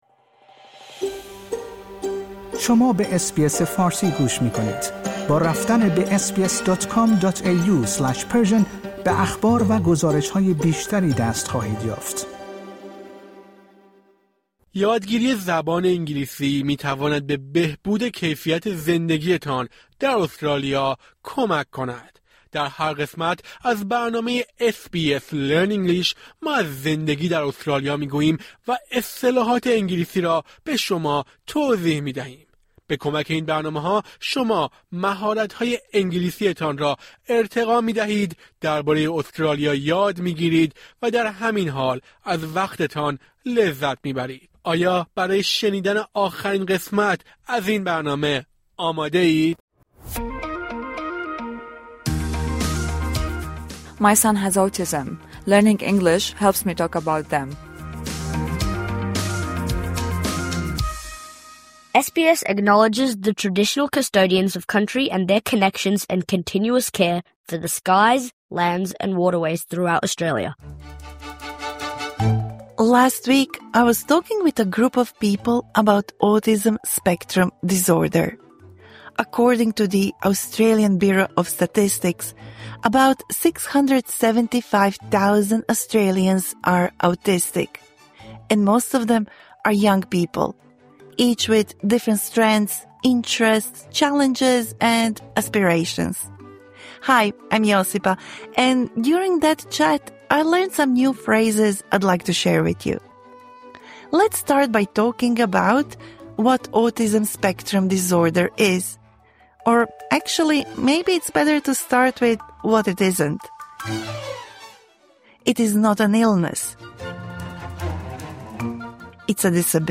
دیالوگ‌ها و عبارات، نمونه‌ خاصی از صحبت‌های بزرگسالان درباره‌ کودکی خردسال است که اخیراً بیماری‌اش تشخیص داده شده است.